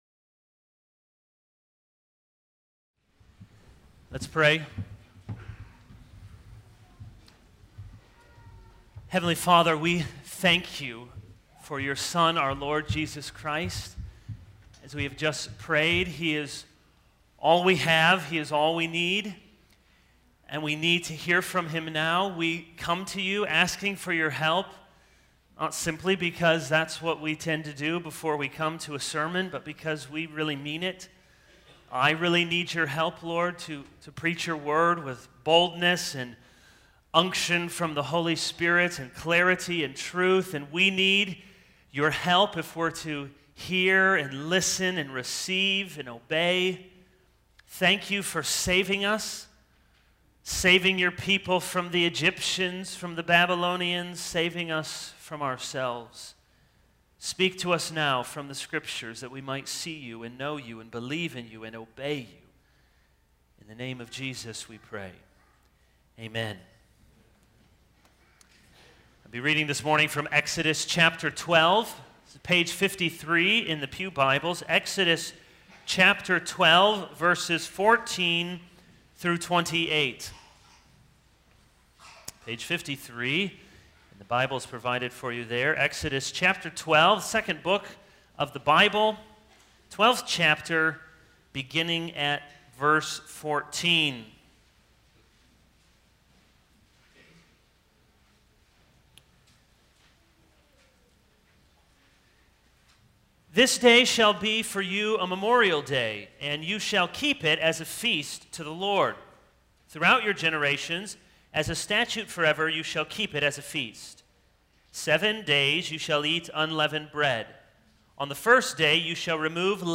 This is a sermon on Exodus 12:14-28.